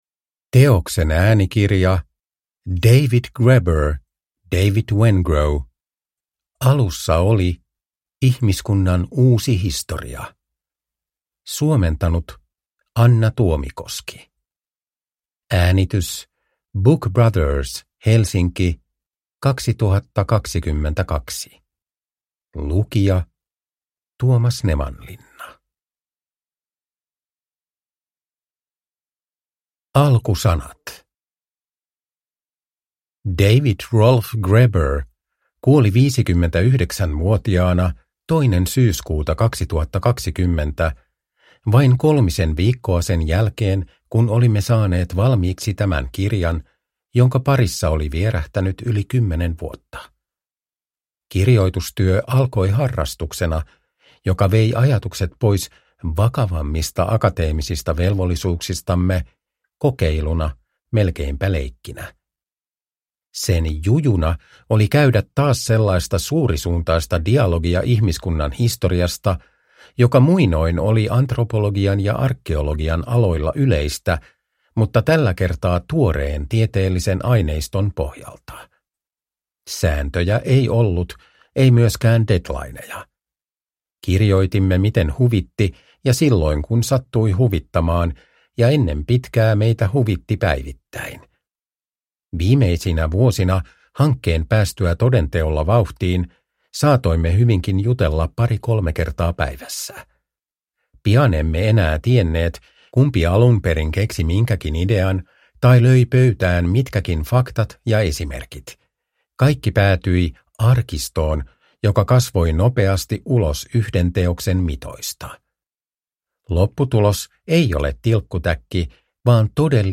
Uppläsare: Tuomas Nevanlinna